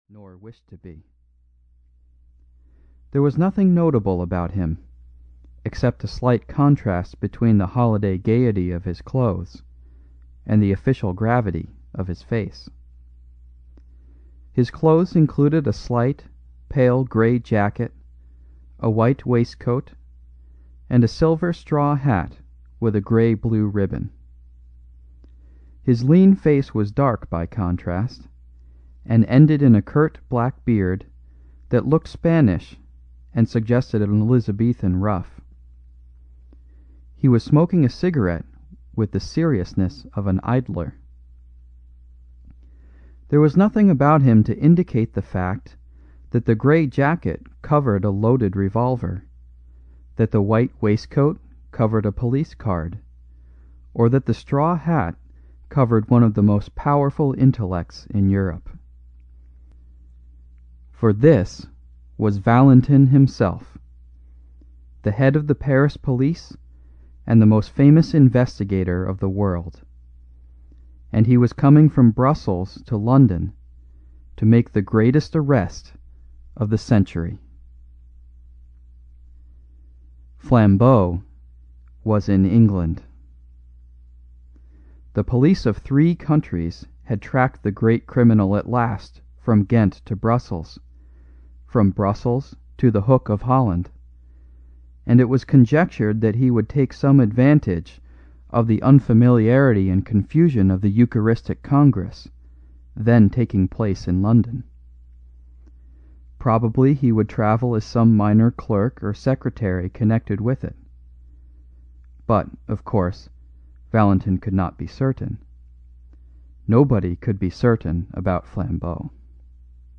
The Innocence of Father Brown (EN) audiokniha
Ukázka z knihy